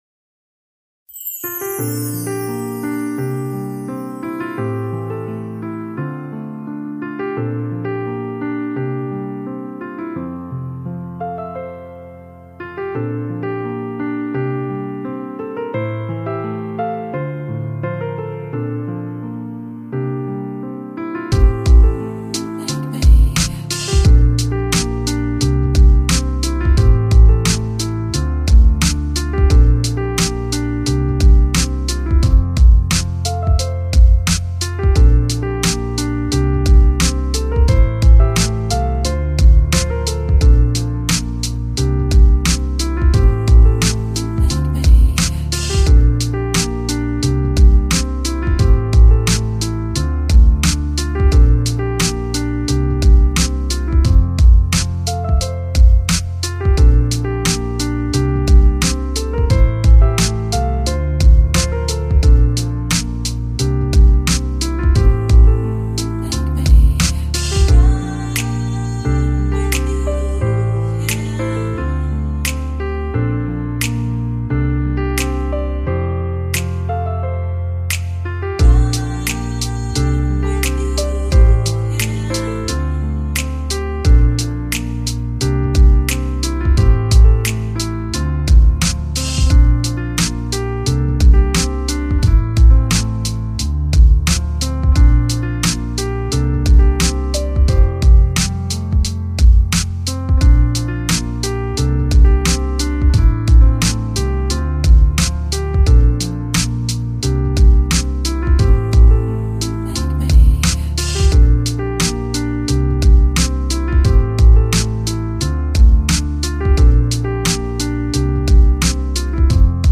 音乐风格: 轻音乐  钢琴
他们的专辑都是以独特的钢琴Hip-Pop为主，非常特别，Hip-Pop的节奏配上忧伤的琴声，十分让人陶醉。